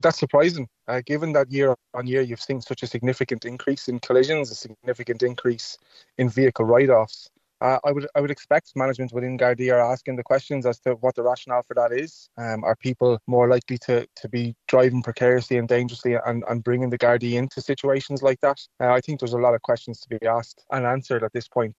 The figures were obtained by Social Democrats TD Aidan Farrelly – he says questions need to be answered: